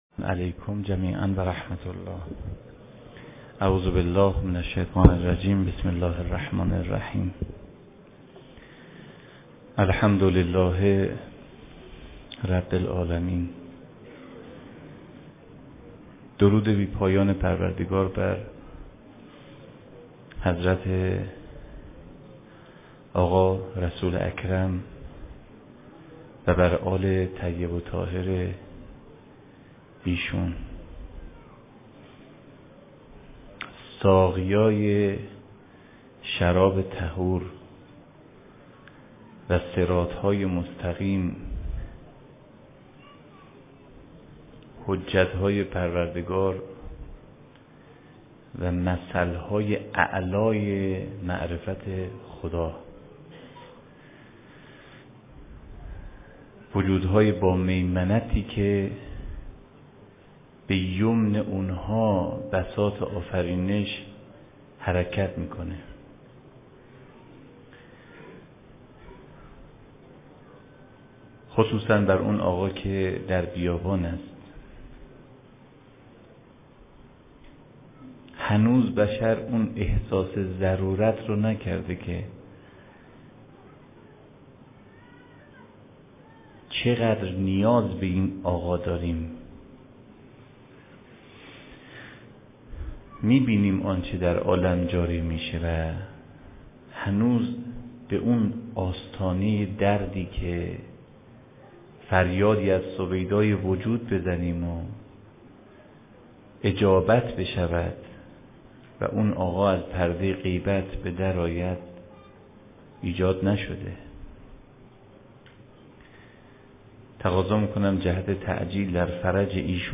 سخنرانی
فاطمیه 93 برازجان